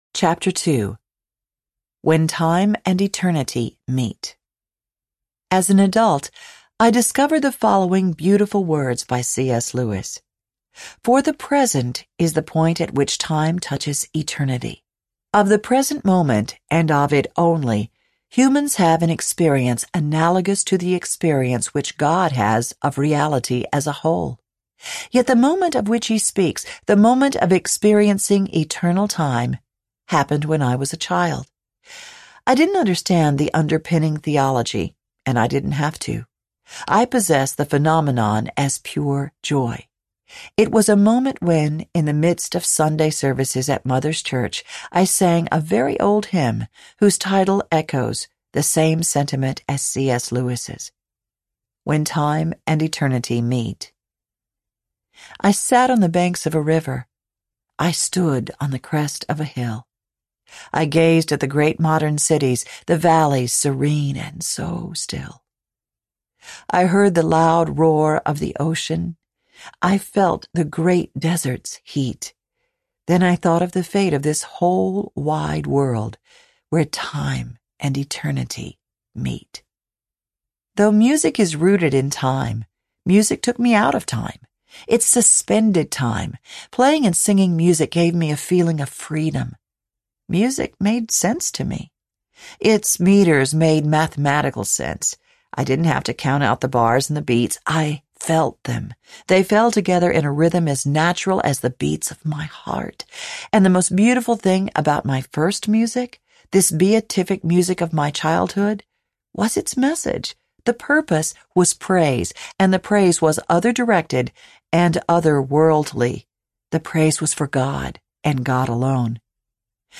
An Outlaw and a Lady Audiobook
Narrator
6.0 Hrs. – Unabridged